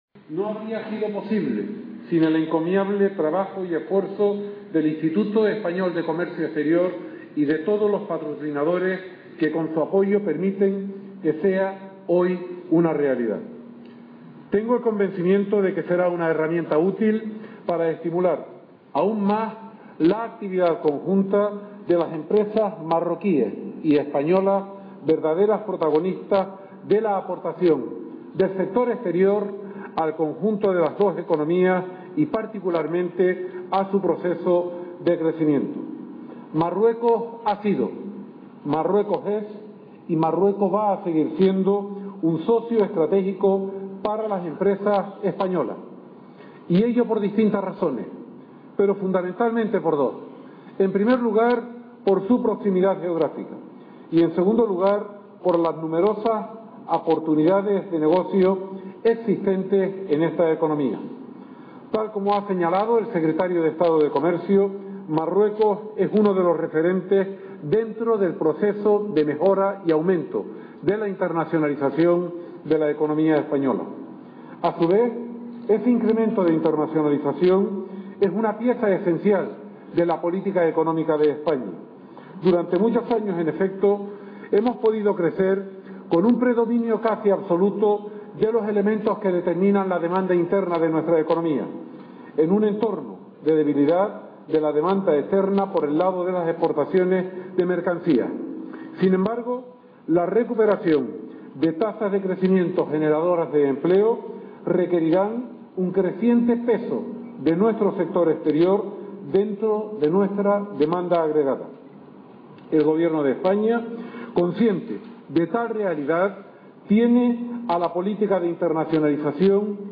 Audio de la Intervención del Ministro